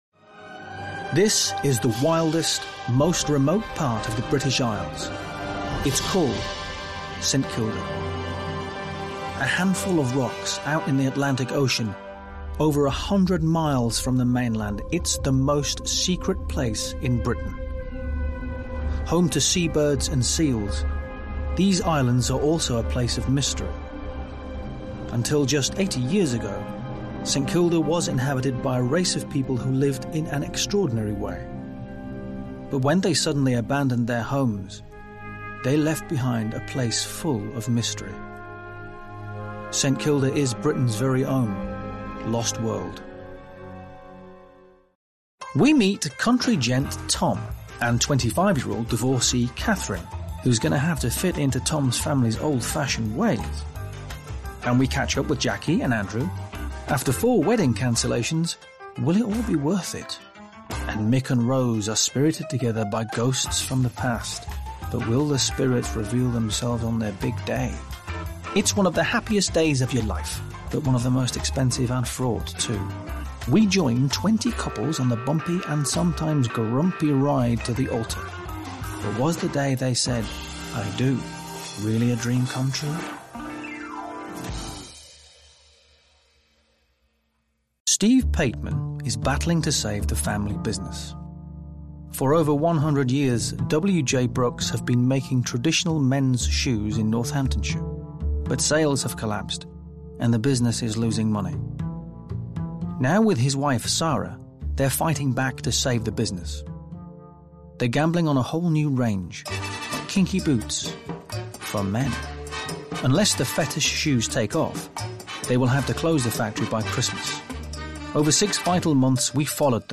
Eng Neutral, Warm, Engaging, Hint of Northern, Versatile, Comical,